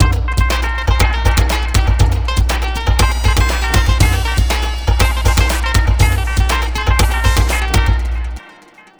Astro 1 Full-C.wav